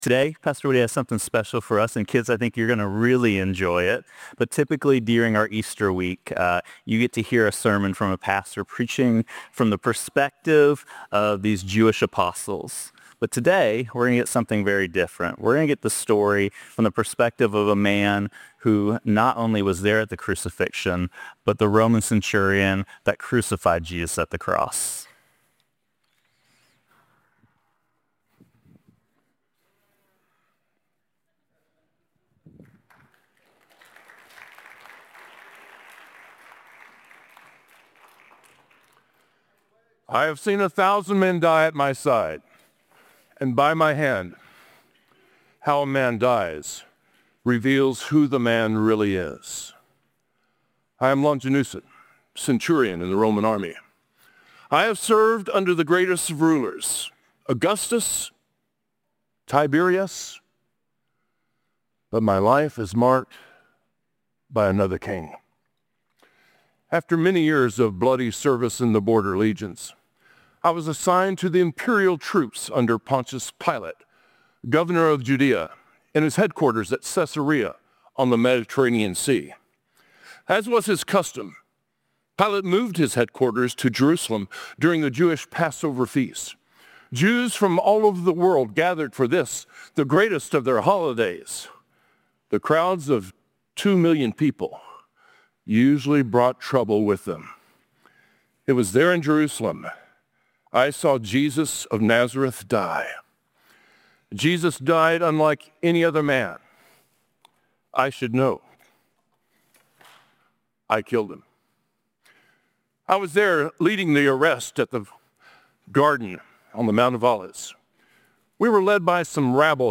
A message from the series "Worship Matters."